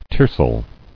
[tier·cel]